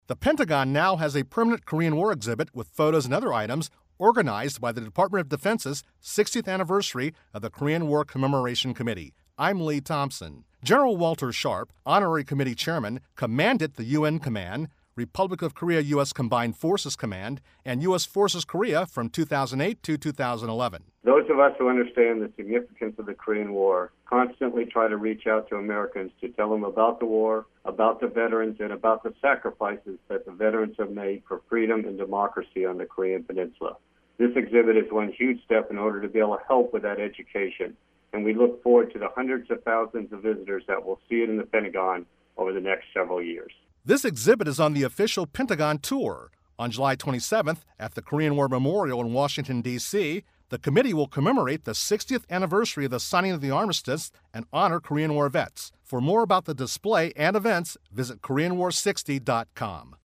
June 18, 2013Posted in: Audio News Release